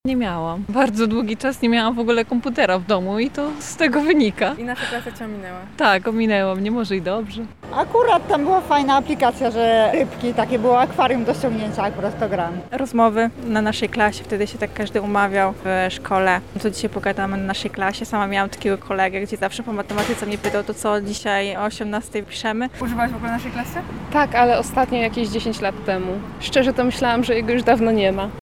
[SONDA] Jak lublinianie wspominają Naszą Klasę?
Zapytaliśmy napotkanych mieszkańców o ich wspomnienia z tym portalem: